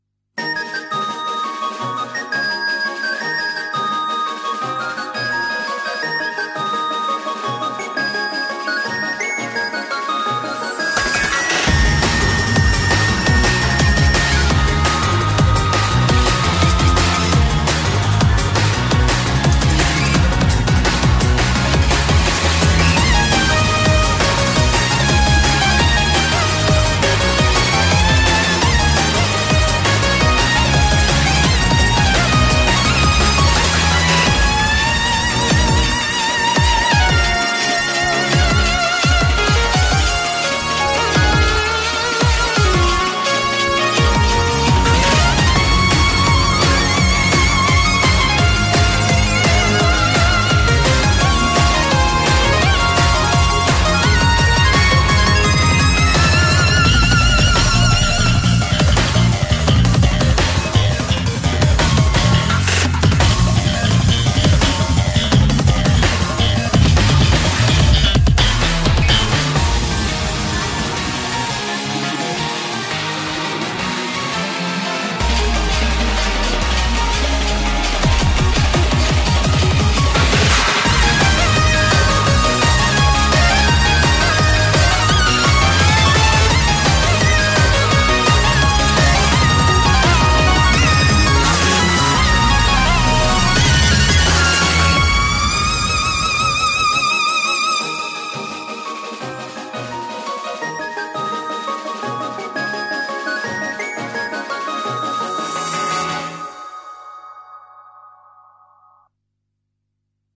BPM85-170
Audio QualityPerfect (High Quality)
I loved the mysterious fantasy vibe from the song